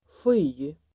Uttal: [sjy:]